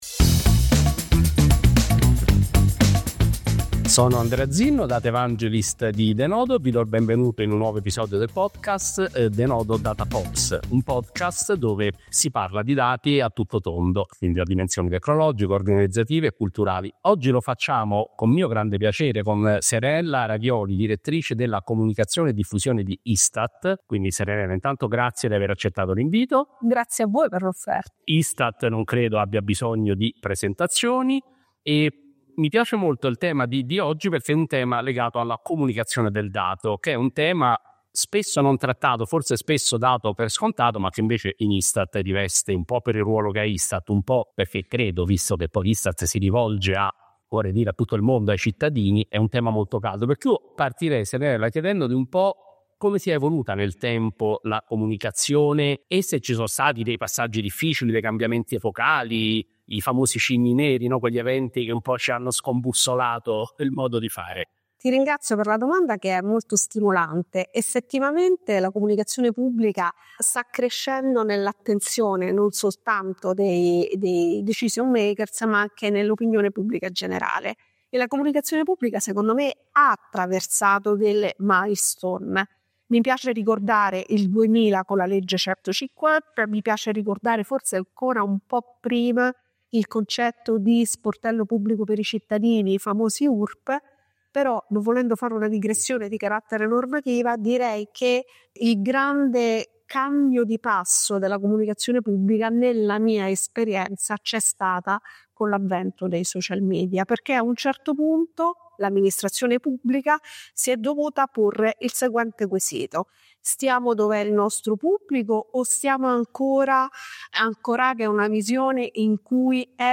La comunicazione del dato, da elemento ancillare a fattore strategico – Una chiacchierata